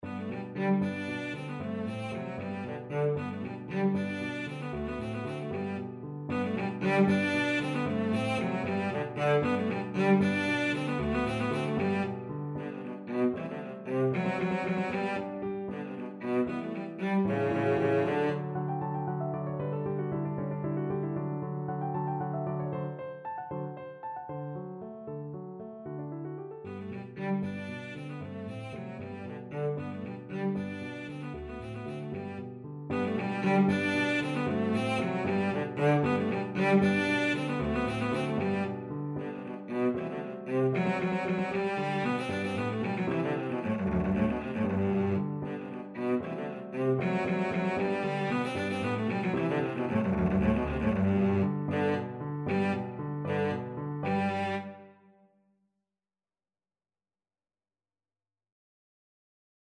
Classical Clementi, Muzio Sonatina Op 36, No 1, Third Movement Cello version
Cello
G major (Sounding Pitch) (View more G major Music for Cello )
Vivace Vivace = 230 (View more music marked Vivace)
3/8 (View more 3/8 Music)
F#3-D5
Classical (View more Classical Cello Music)